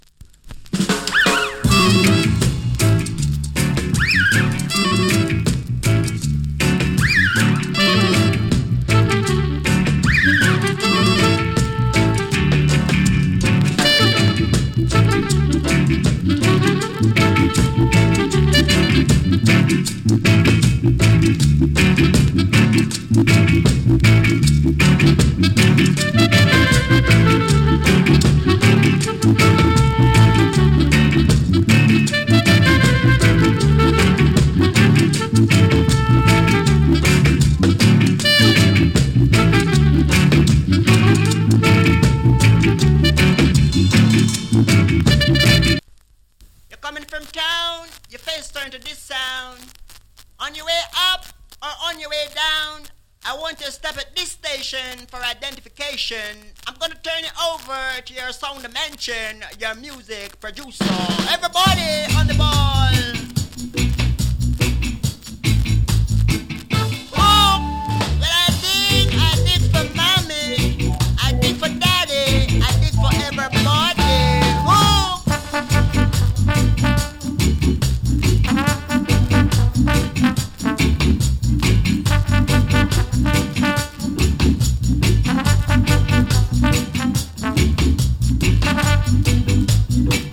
チリ、ジリノイズ有り。わずかに音ワレ有り。
69年 GREAT HORN INST !